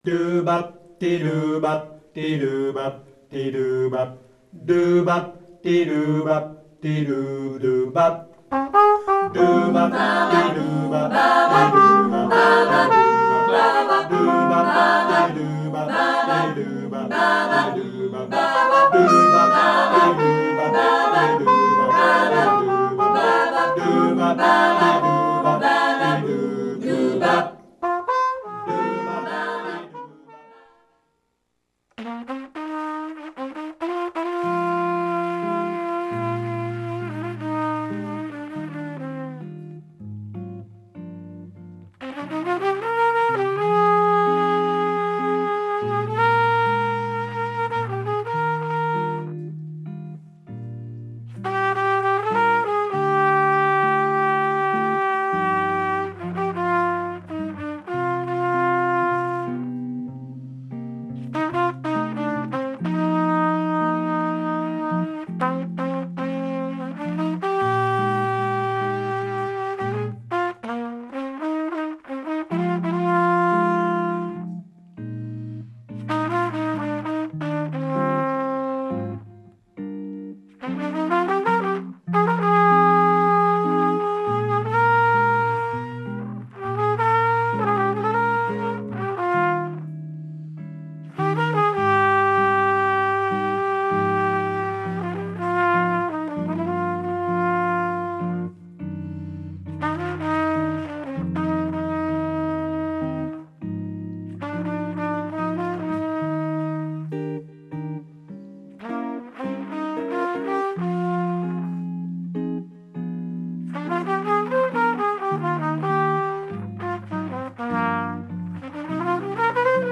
Pendant cinquante deux minutes, des musiciens et musiciennes se confient sur leur réalité de vie d'artistes, en partagent leurs musiques préférées, et en jouant quelques notes avec moi. Dans une ambiance tranquille et chaleureuse.